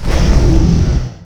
combat / creatures / dragon
attack3.wav